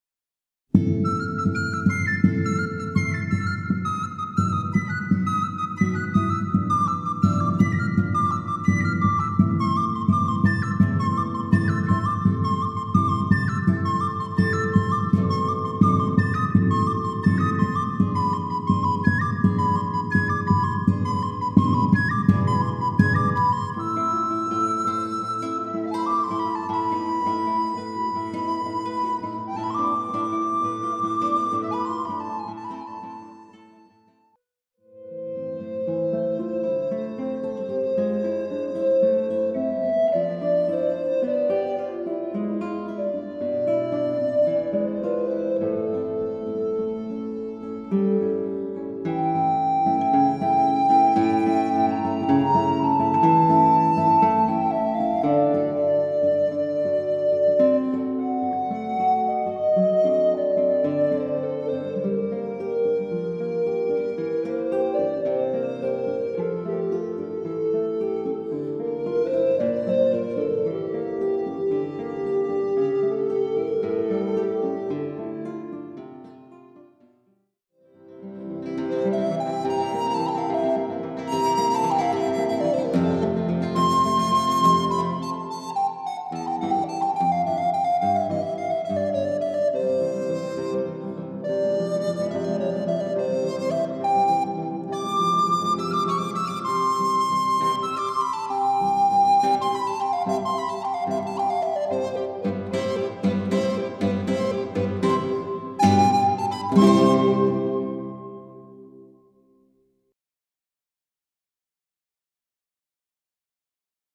für Blockflöte und Gitarre
Musik für Blockflöte und Gitarre